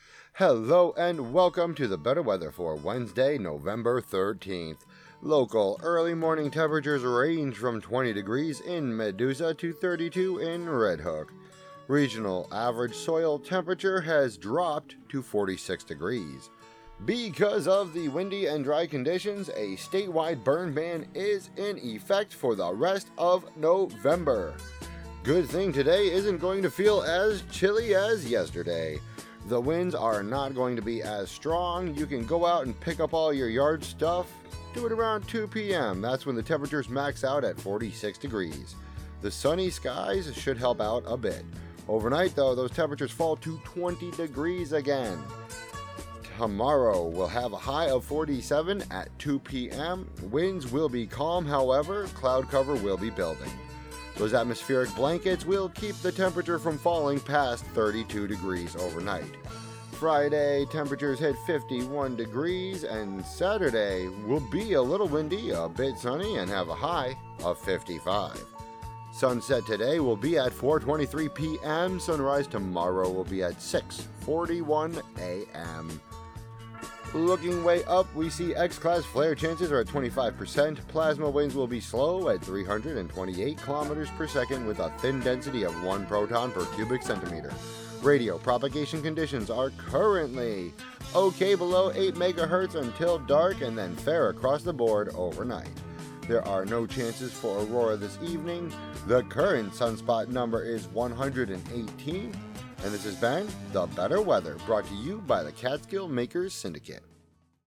broadcasts